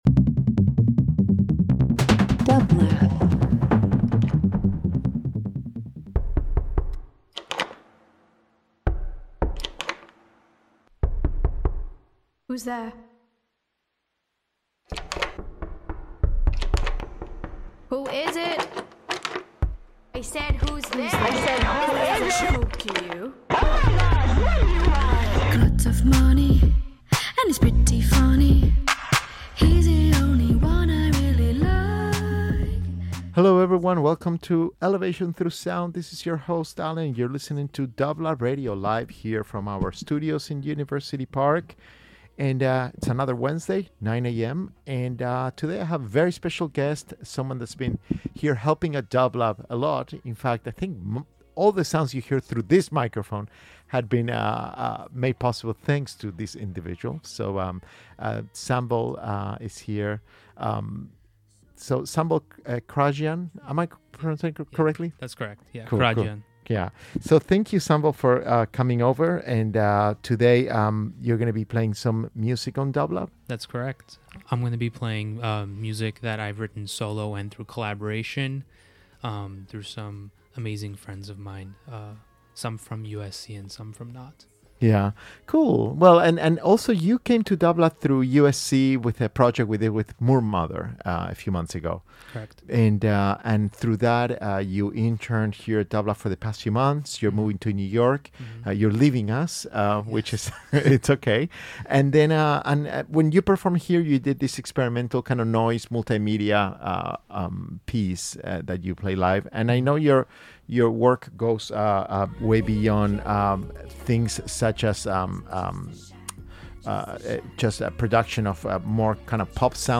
Electronic Experimental Noise